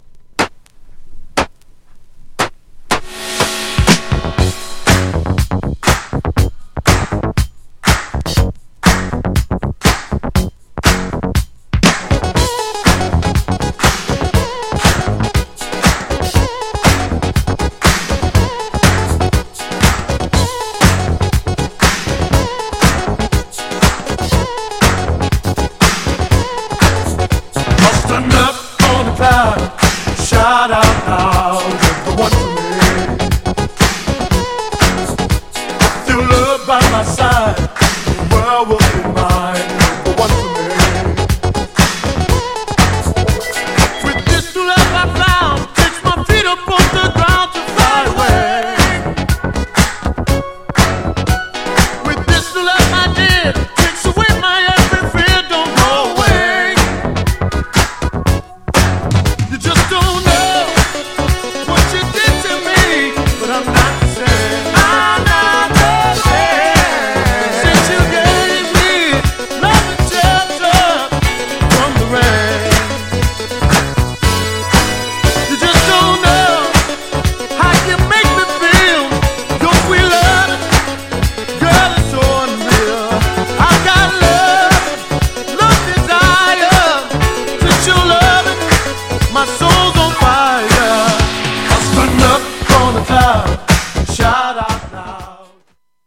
GENRE Dance Classic
BPM 121〜125BPM